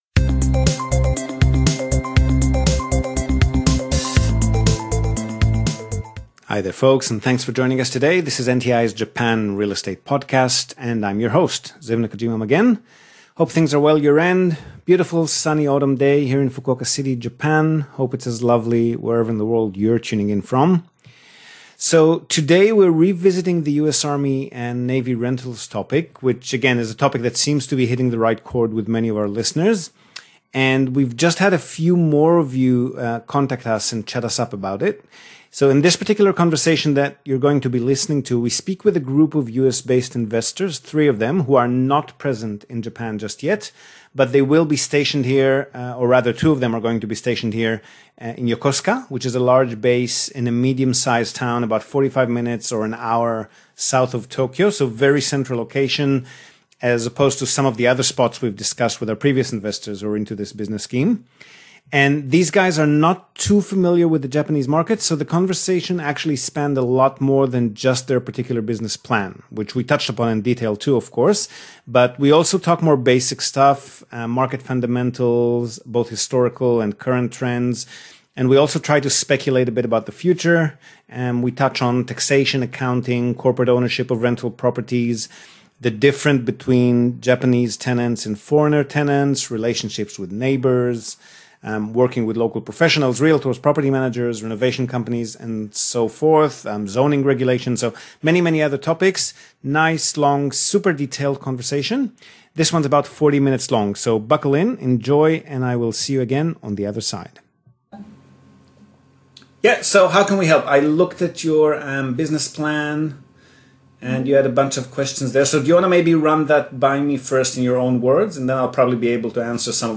We speak to 3 entrepreneurs from the USA who are learning about Japan's real-estate property market, planning to kick off their army/navy personnel rental business near Yokosuka base, an hour south of Tokyo.
Conversation with Aomori US army investor